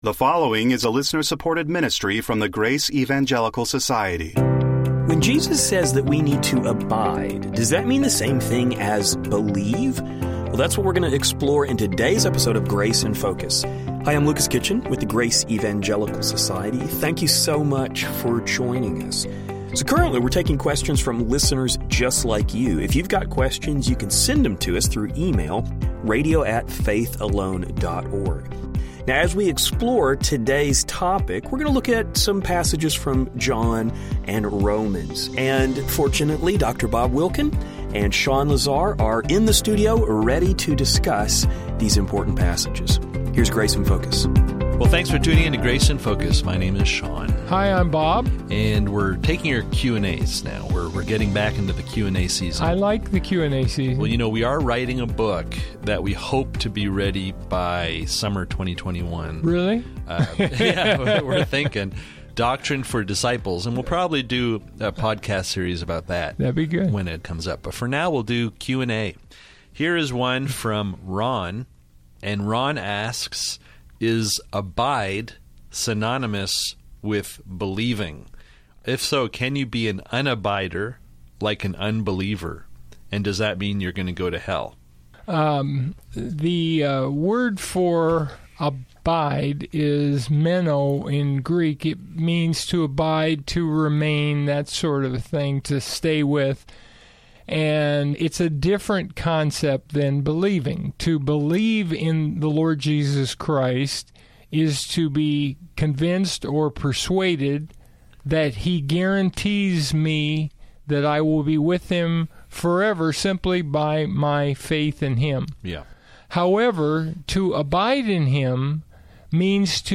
Today on Grace in Focus radio, we are answering our listeners’ questions.